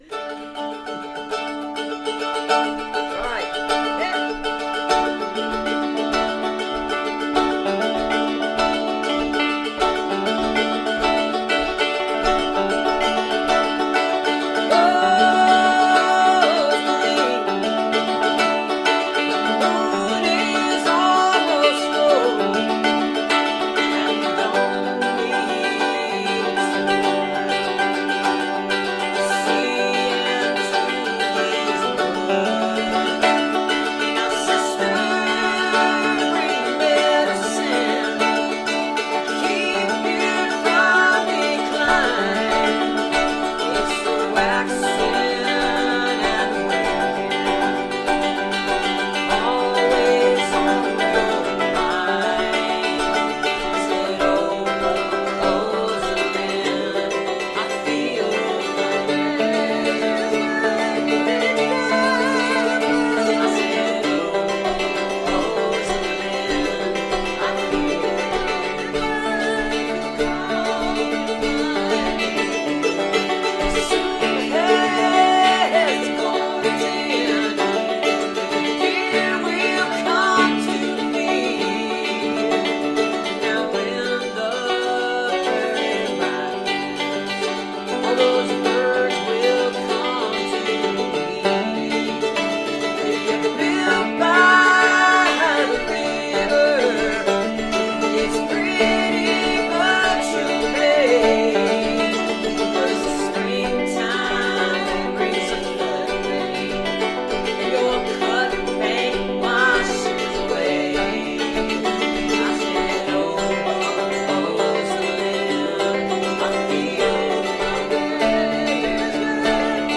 (captured from the facebook livestream)